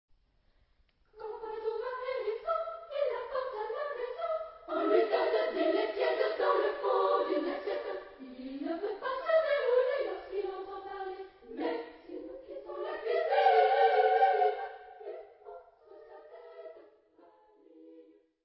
Type de choeur : SSA  (3 voix égale(s) d'enfants )
Tonalité : la mineur